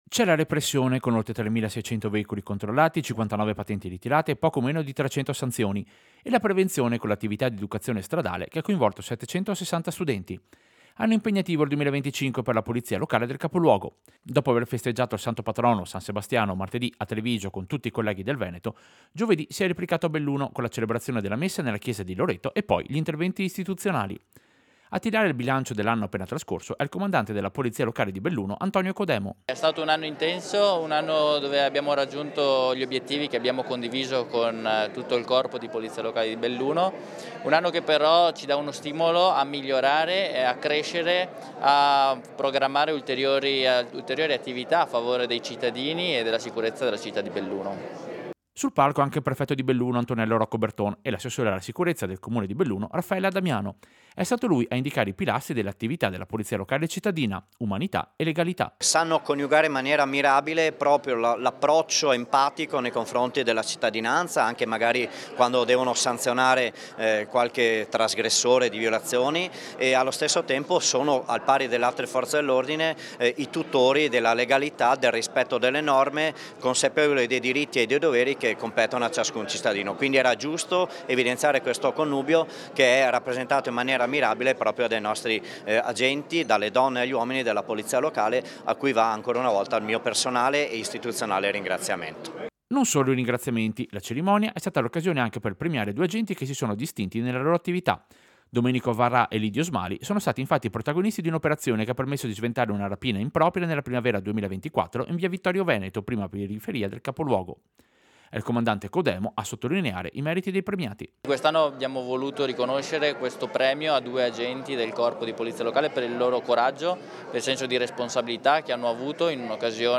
Servizio-Festa-Polizia-Locale-Belluno-2026.mp3